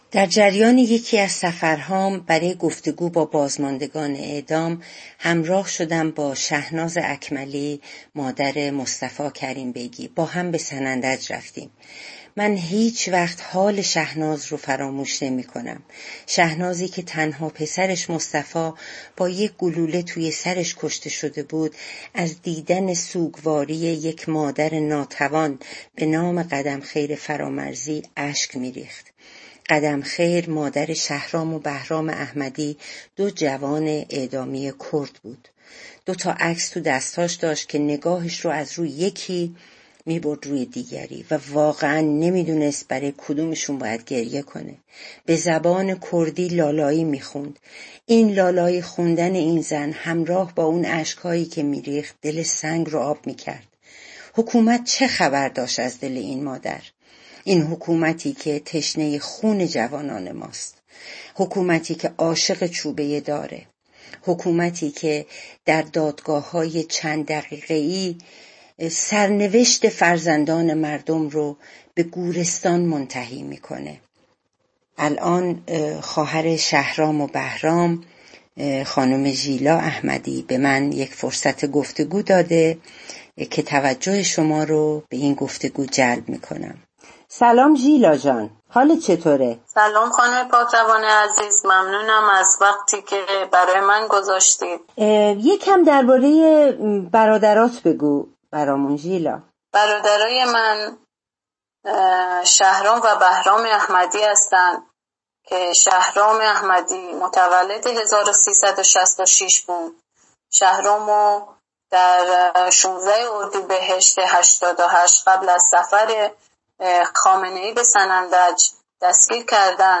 گفت و شنود
با او گفتگویی کرده ایم که میشنوید: